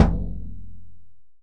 GR.TROMMEL 1.wav